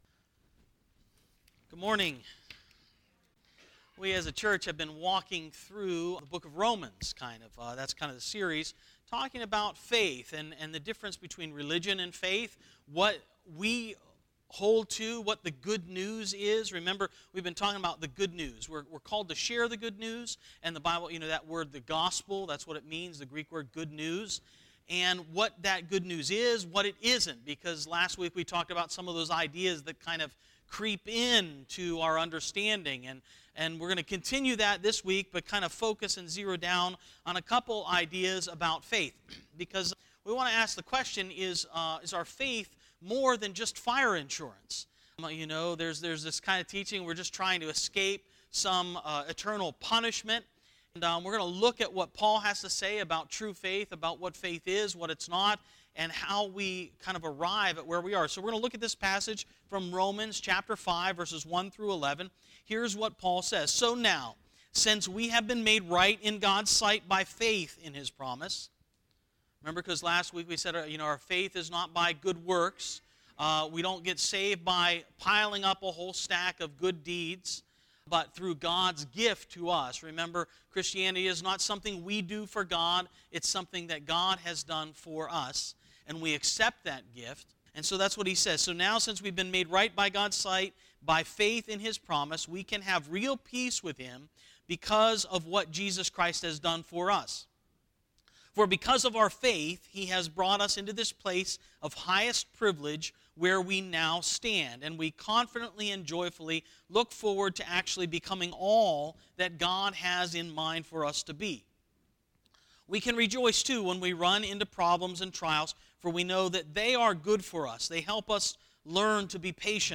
2-10-19 Sermon